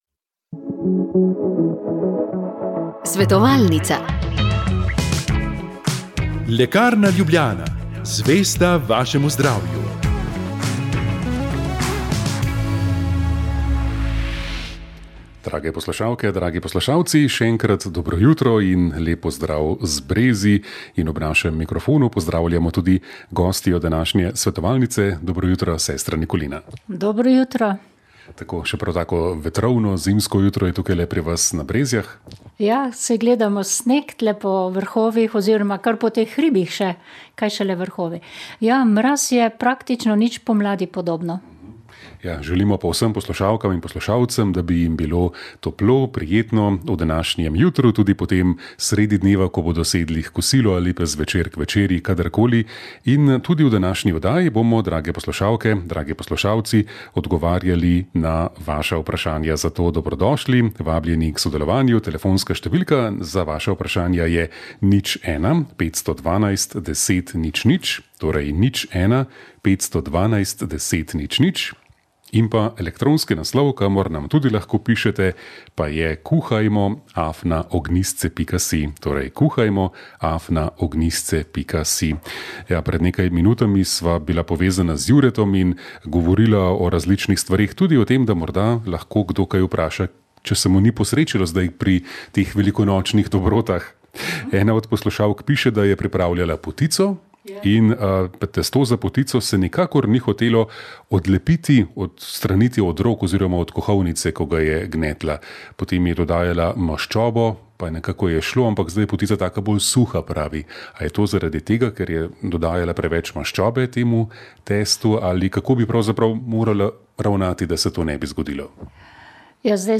Različni nasveti za pripravo potice (testo se prijema rok, makov nadev se kisa ...), kuhanje mlečnega riža, rob pri palačinkah je trd, kvas pri štrukljih ... so bile teme v tokratni kontaktni oddaji